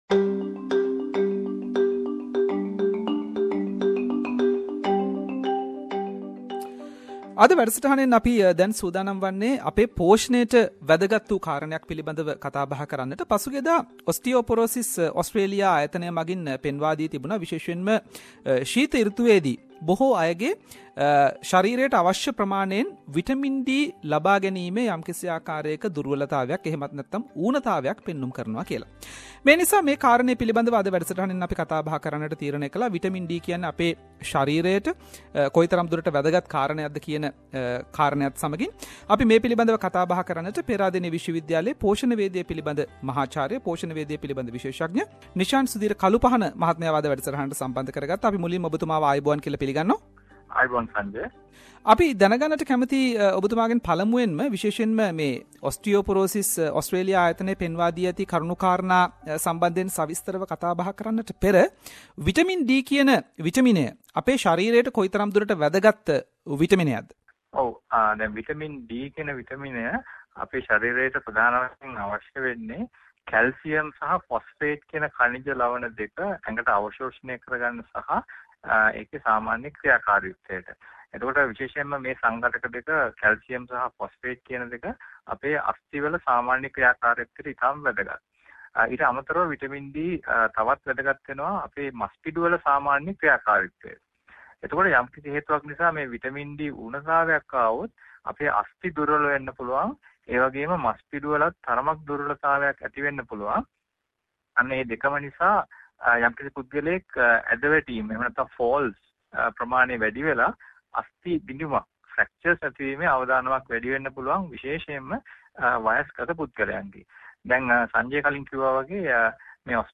Special interview